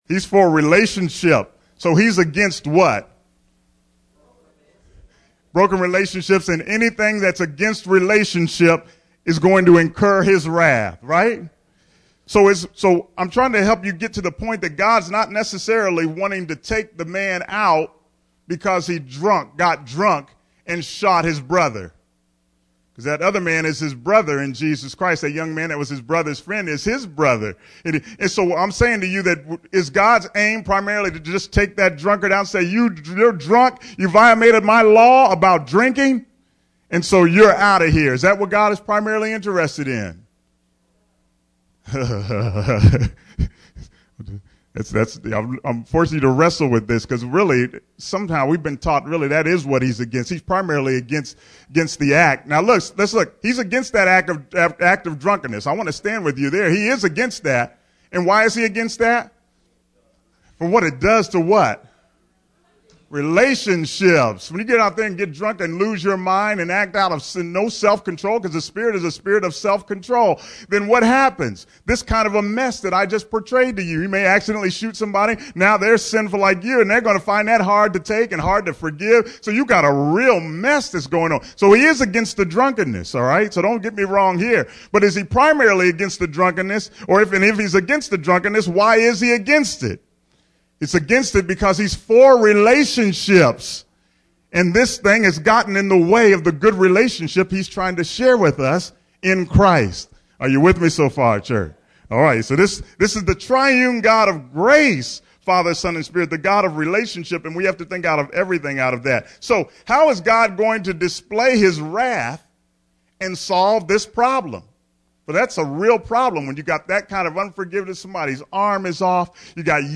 Sermon: Jesus – God’s Relationship Solution (NOT Penal Substitution!)